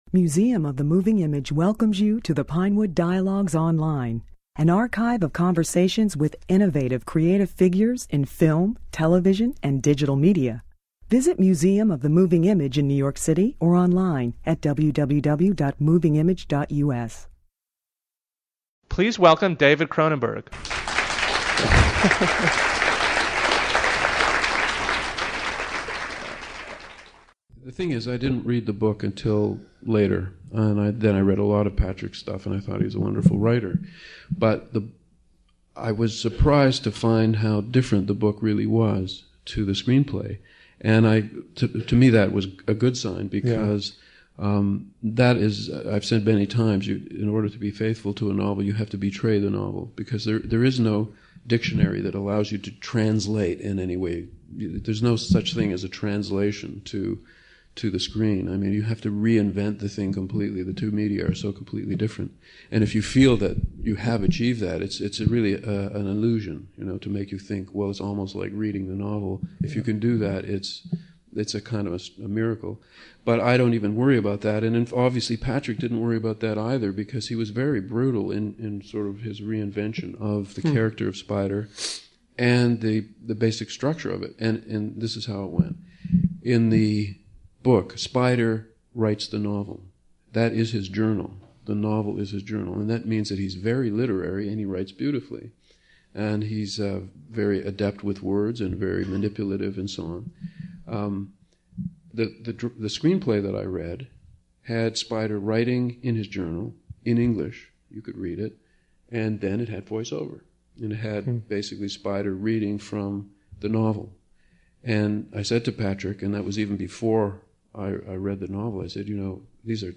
A decade after his complete retrospective at the Museum, Cronenberg returned to Moving Image to discuss Spider , his adaptation of Patrick McGrath's novel about a schizophrenic whose tenuous hold on reality is threatened by fragmented memories of a family trauma.